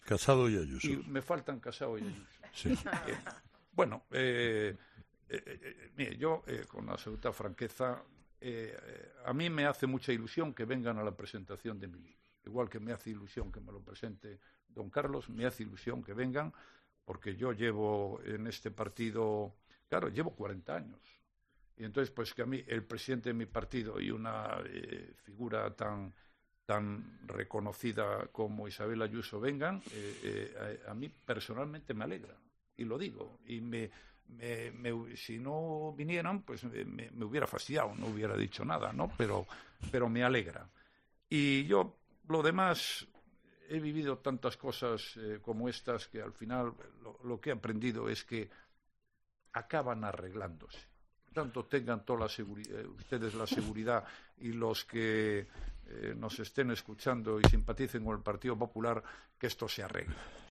El expresidente del Gobierno Mariano Rajoy sostiene que las posibles diferencias que puedan existir entre dos pesos pesados de su partido como son Pablo Casado e Isabel Díaz Ayuso "se acabarán arreglando". En su entrevista este viernes con Carlos Herrera en COPE, el exlíder del PP ha admitido que le hace "ilusión" que ambos asistan al acto de presentación de su libro, que tendrá lugar este miércoles.